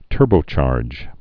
(tûrbō-chärj)